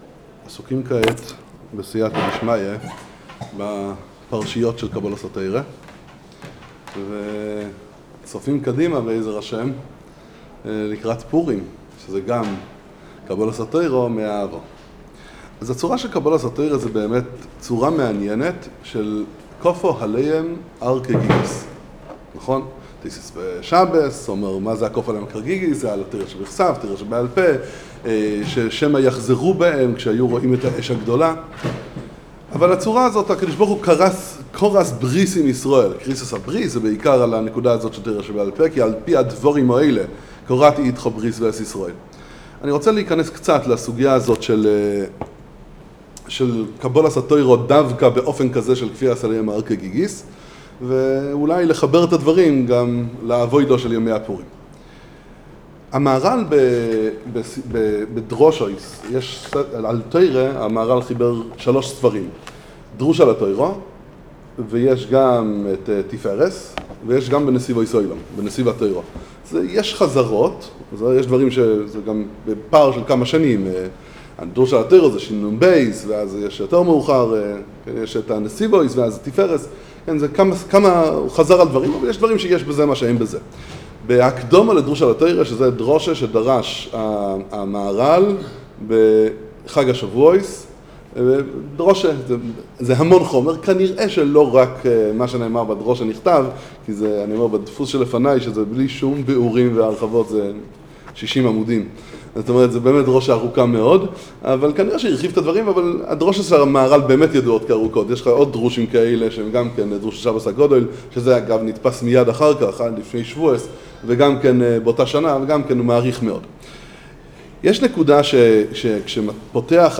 למה היה צריך בקבלת התורה 'כפה עליהם הר כגיגית'? שיעור עומק בדברי המהר"ל והכוזרי - פרשת יתרו, משפטים